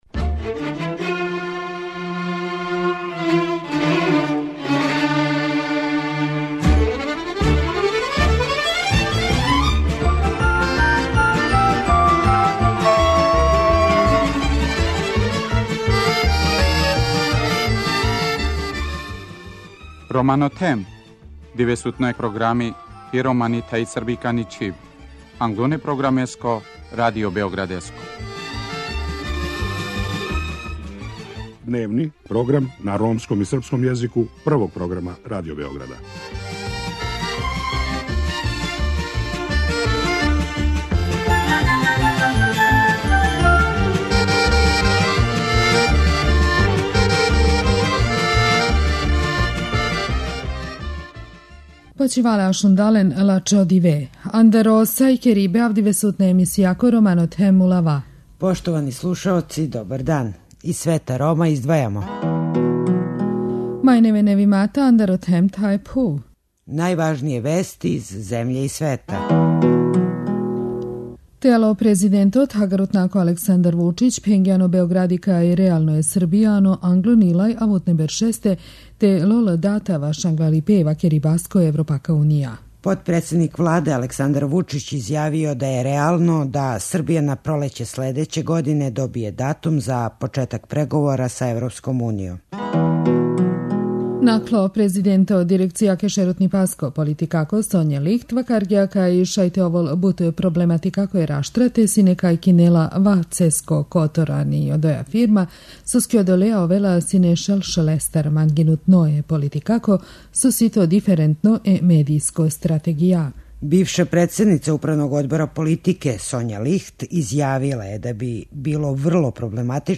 Одложено је принудно исељење ромских породица из напуштених хала новобеоградске фабрике 'Борац'. Поред репортаже о Ромима из 'Борца' у емисији најављујемо и хуманитарну изложбу фотографија у резиденцији британског амбасадора.